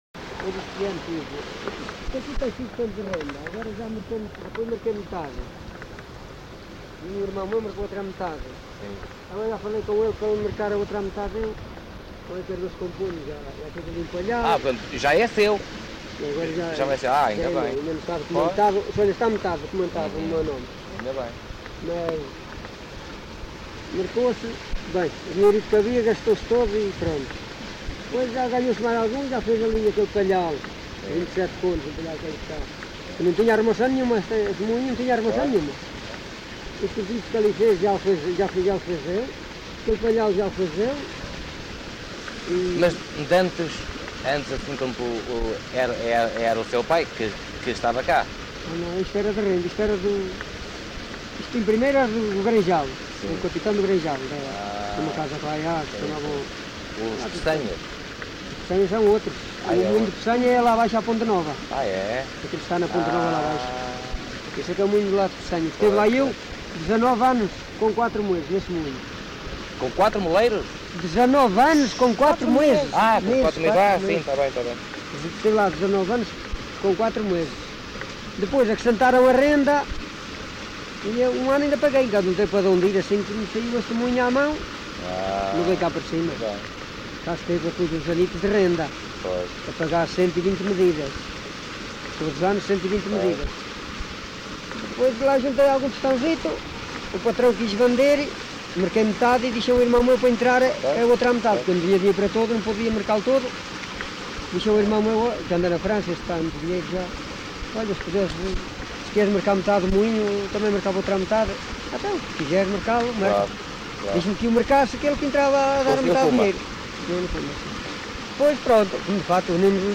LocalidadeGranjal (Sernancelhe, Viseu)